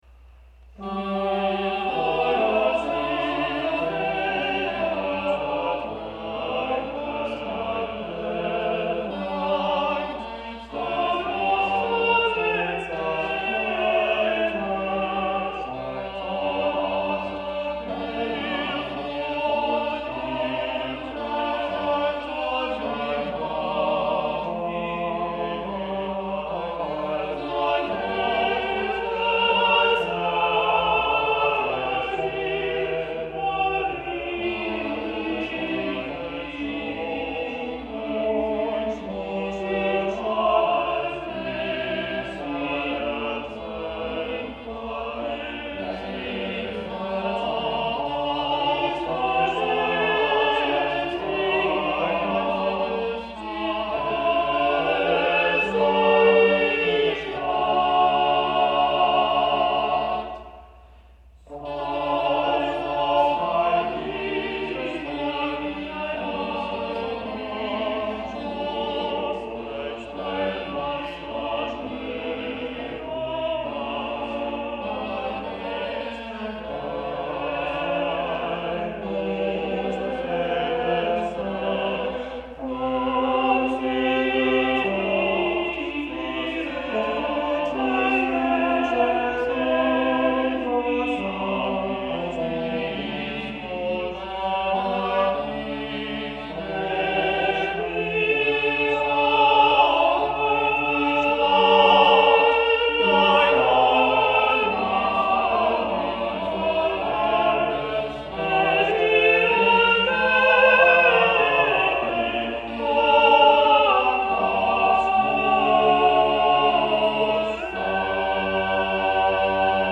In this case a perfectly beautiful melody by the Englishman, Johannes Bedyngham, is successively counterpointed by 20-odd snippets of German love songs.